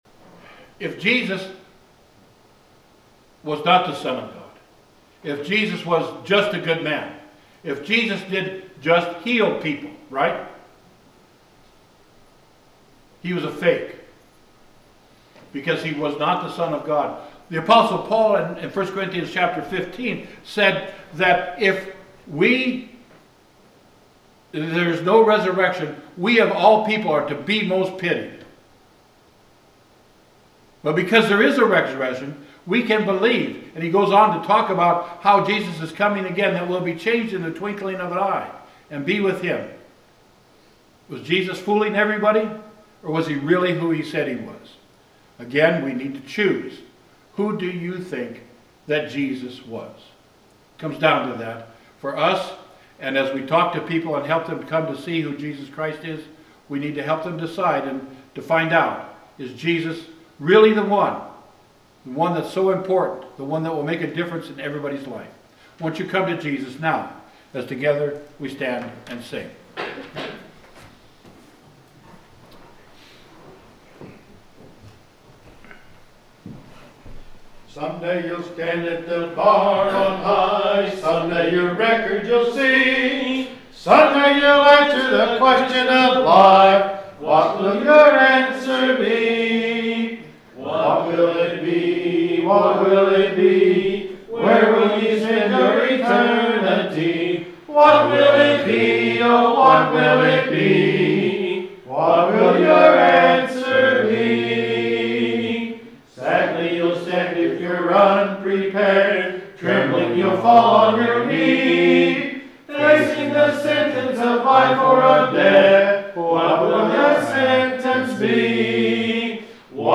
The sermon is from our live stream on 11/23/2025